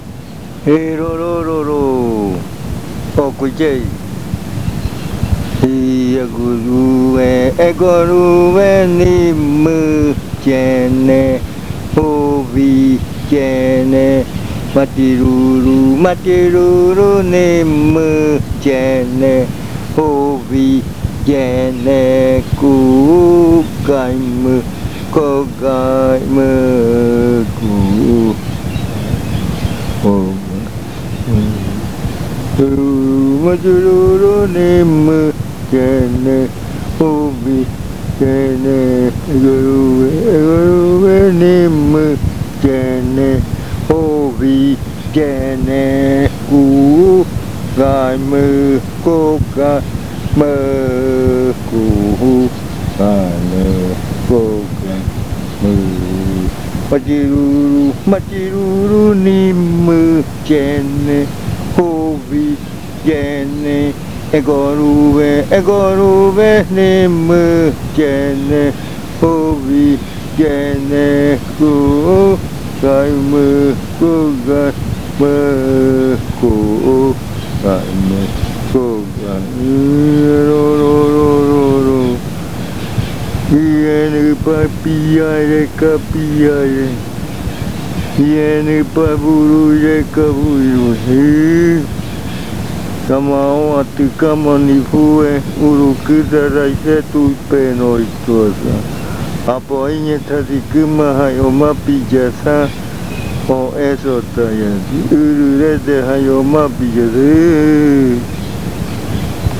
Canto que se canta media noche. Canto que tiene exclamaciones con otras variaciones dialectales. El murui que sabe esta canción la canta para animar la fiesta.
A chant that has exclamations with other dialect variations, the murui who knows this chant sings them to liven up the ritual.